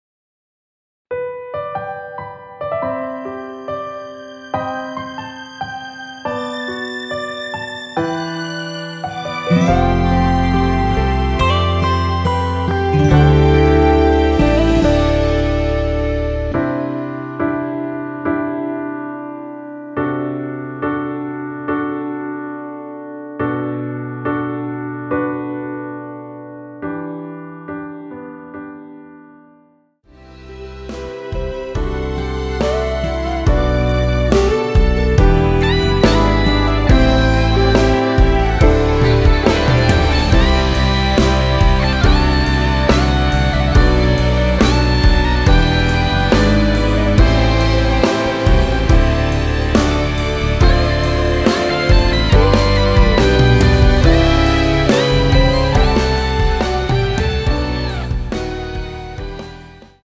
원키에서(+4)올린 MR입니다.
앞부분30초, 뒷부분30초씩 편집해서 올려 드리고 있습니다.
중간에 음이 끈어지고 다시 나오는 이유는